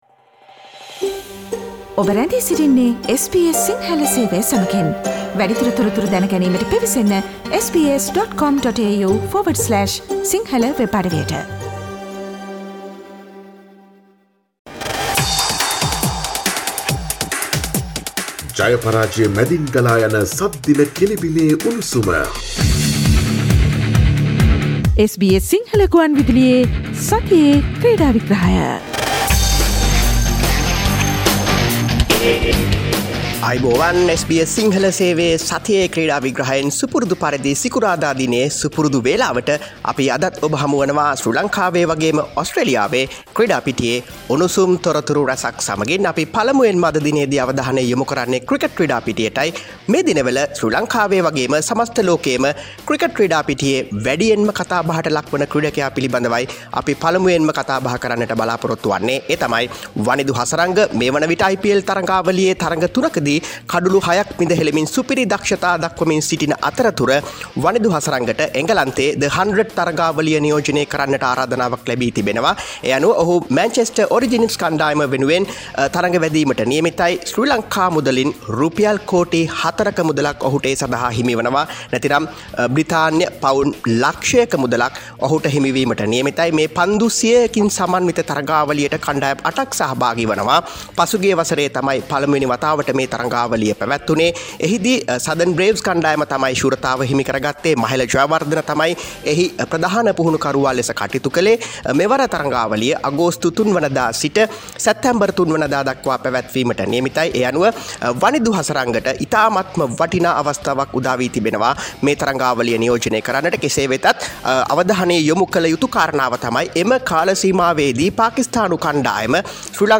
SL Cricketer Wanindu Hasaranga gets 40 million rupees for "The Hundred" in England : SBS Sinhala weekly sports wrap